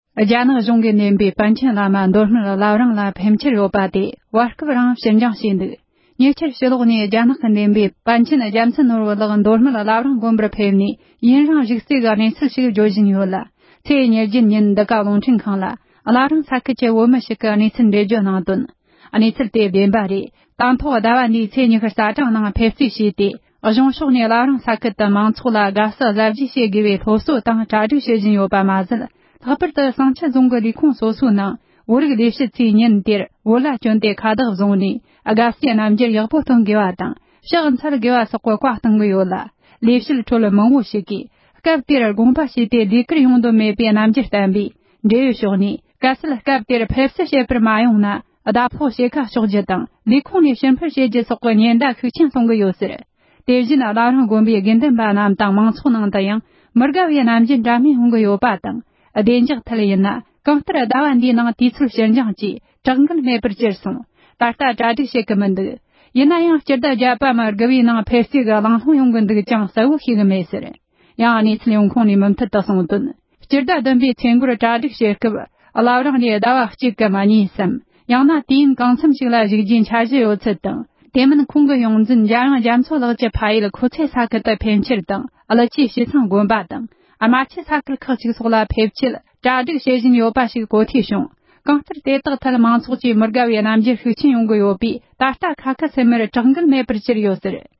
༄༅༎ཉེ་ཆར་ཕྱི་ལོག་ནས་རྒྱ་ནག་གིས་འདེམས་པའི་པཎ་ཆེན་རྒྱལ་མཚན་ནོར་བུ་ལགས་མདོ་སྨད་བླ་བྲང་དགོན་པར་ཕེབས་ནས་ཡུན་རིང་བཞུགས་རྩིས་ཀྱི་གནས་ཚུལ་ཞིག་བརྗོད་བཞིན་ལ།ཚེས་༢༨ཉིན་འདི་ག་རླུང་འཕྲིན་ཁང་ལ་བླ་བྲང་ས་ཁུལ་གྱི་བོད་མི་ཞིག་གིས་གནས་ཚུལ་འགྲེལ་བརྗོད་གནང་དོན། གནས་ཚུལ་དེ་བདེན་པ་རེད། དེང་ཐོག་ཟླ་བ་འདིའི་ཚེས་ཉི་ཤུ་རྩ་གྲངས་ནང་ཕེབས་རྩིས་བྱས་ཏེ་། གཞུང་ཕྱོགས་ནས་བླ་བྲང་ས་ཁུལ་དུ་མང་ཚོགས་ལ་དགའ་བསུ་གཟབ་རྒྱས་བྱེད་དགོས་པའི་སློབ་གསོ་དང་གྲ་སྒྲིག་བྱེད་བཞིན་ཡོད་པ་མ་ཟད། ལྷག་པར་དུ་བསང་ཆུ་རྫོང་གི་ལས་ཁུངས་སོ་སོའི་ནང་བོད་རིགས་ལས་བྱེད་ཚོས་ཉིན་དེར་བོད་ལྭ་གྱོན་ཏེ་ཁ་བཏགས་བཟུང་ནས་དགའ་བསུའི་རྣམ་འགྱུར་ཡག་པོ་སྟོན་དགོས་པ་དང་། ཕྱག་འཚལ་དགོས་པ་སོགས་ཀྱི་བཀའ་གཏོང་གི་ཡོད་ལ། ལས་བྱེད་ཁྲོད་མང་པོ་ཞིག་གིས་སྐབས་དེར་དགོངས་པ་ཞུས་ཏེ་ལས་ཀར་ཡོང་འདོད་མེད་པས་རྣམ་འགྱུར་བསྟན་པས། འབྲེལ་ཡོད་ཕྱོགས་ནས་གལ་སྲིད་སྐབས་དེར་ཕེབས་བསུ་མ་བྱས་ན། ཟླ་ཕོགས་ཕྱེད་ཀ་གཅོག་རྒྱུ་དང་། ལས་ཁུངས་ནས་ཕྱིར་འཕུད་བྱེད་རྒྱུ་སོགས་ཀྱི་ཉེན་བརྡ་ཤུགས་ཆེན་གཏོང་གི་ཡོད་ཟེར། དེ་བཞིན་བླ་བྲང་དགོན་པའི་དགེ་འདུན་པ་རྣམས་དང་མང་ཚོགས་ནང་དུའང་མི་དགའ་བའི་རྣམ་འགྱུར་འདྲ་མིན་ཡོང་གི་ཡོད་པ་དང་། བདེ་འཇགས་ཐད་ཡིན་ན་གང་ལྟར་ཟླ་བ་འདིའི་ནང་དུས་ཚོད་ཕྱིར་འགྱངས་ཀྱིས་གྲགས་འགུལ་མེད་པར་གྱུར་སོང་། ད་ལྟ་གྲ་སྒྲིག་བྱེད་ཀྱི་མི་འདུག ཡིན་ནའང་སྤྱི་ཟླ་བརྒྱད་པའམ་དགུ་བའི་ནང་ཕེབས་རྩིས་ཀྱི་གླེང་སློང་ཡོང་གི་འདུག་ཀྱང་གསལ་པོ་ཤེས་ཀྱི་མེད་ཟེར། ཡང་གནས་ཚུལ་ཡོང་ཁུངས་ནས་མུ་མཐུད་དུ་གསུང་དོན། སྤྱི་ཟླ་བདུན་པའི་ཚེས་འགོར་གྲ་སྒྲིག་བྱེད་སྐབས་།བླ་བྲང་ནས་ཟླ་བ་གཅིག་གཉིས་སམ་དུས་ཡུན་གང་འཚམ་ལ་བཞུགས་རྒྱུའི་འཆར་གཞི་ཡོད་ཚུལ་་དང་། དེ་མིན་ཁོང་གི་ཡོངས་འཛིན་འཇམ་དབྱངས་རྒྱ་མཚོ་ལགས་ཀྱི་ཕ་ཡུལ་ཁོ་ཚེ་ས་ཁུལ་དུ་ཕེབས་འཆར་དང་།ཀླུ་ཆུའི་བྱེས་ཚང་དགོན་པ་དང་རྨ་ཆུའི་ས་ཁུལ་ཁག་ཅིག་སོགས་ལ་ཕེབས་ཆེད་གྲ་སྒྲིག་བྱེད་བཞིན་ཡོད་པ་ཞིག་གོ་ཐོས་བྱུང་། གང་ལྟར་དེ་དག་ཐད་མང་ཚོགས་ཀྱིས་མི་དགའ་བའི་རྣམ་འགྱུར་ཤུགས་ཆེན་ཡོང་གི་ཡོད་པས་། ད་ལྟ་ཁི་སུམ་མེར་གྲགས་འགུལ་མེད་པར་གྱུར་ཡོད་ཟེར།།